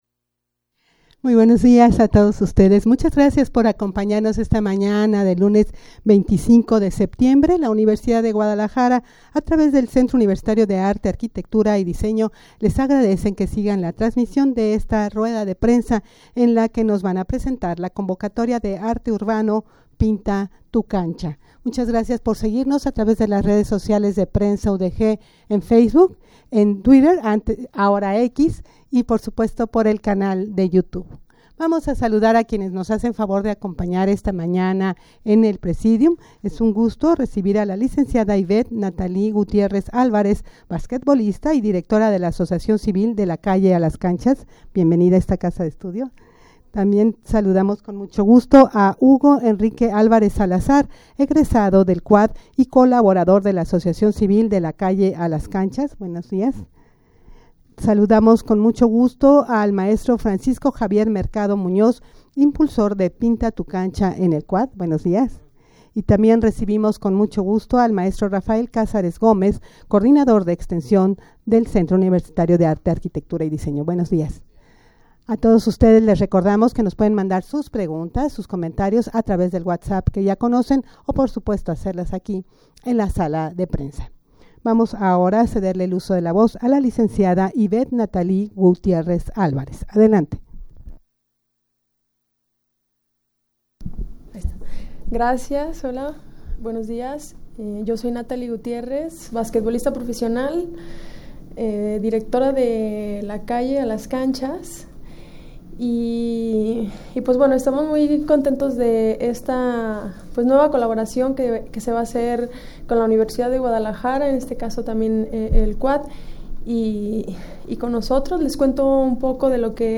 Audio de la Rueda de Prensa
rueda-de-prensa-para-presentar-la-convocatoria-de-arte-urbano-pinta-tu-cancha.mp3